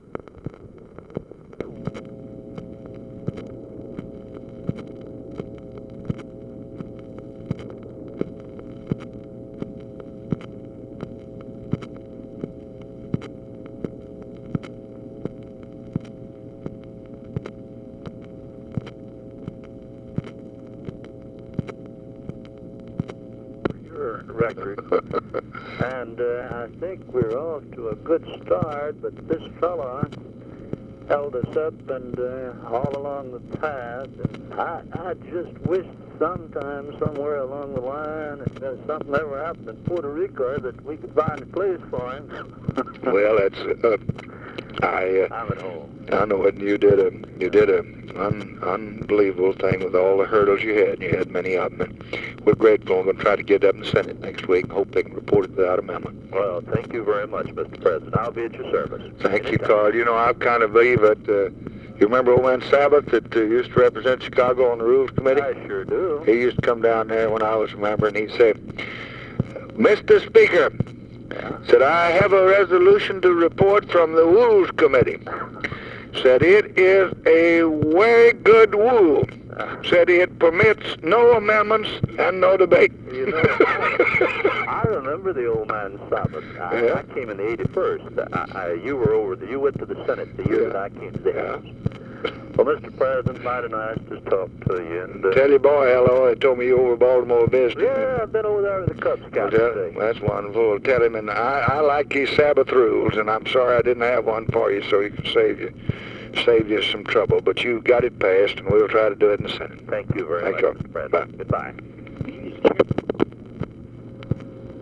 LBJ CONGRATULATES PERKINS ON PASSAGE OF EDUCATION BILL; PERKINS COMMENTS ON ADAM CLAYTON POWELL'S HANDLING OF BILL; LBJ MIMICS CONGRESSMAN ADOLPH SABATH'S SPEECHES TO CONGRESS REPORTING RESOLUTIONS FROM HOUSE RULES COMMITTEE
Conversation with CARL PERKINS, March 27, 1965
Secret White House Tapes